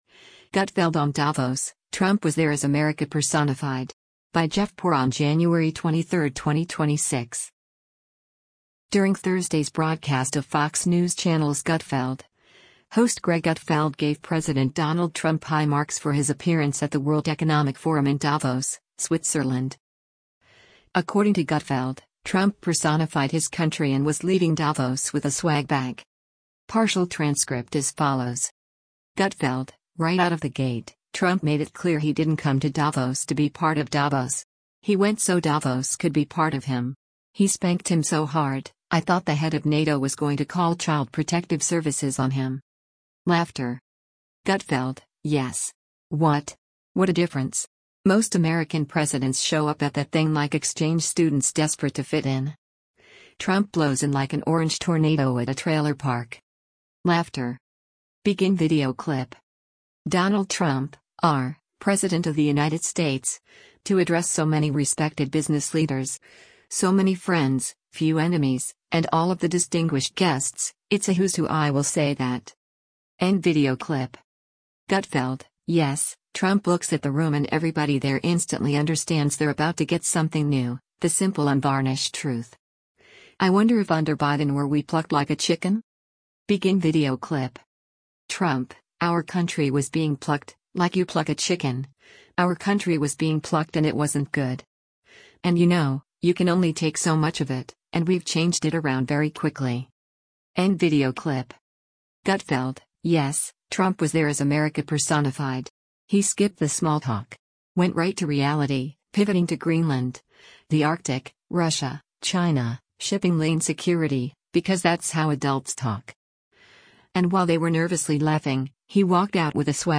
During Thursday’s broadcast of Fox News Channel’s “Gutfeld!,” host Greg Gutfeld gave President Donald Trump high marks for his appearance at the World Economic Forum in Davos, Switzerland.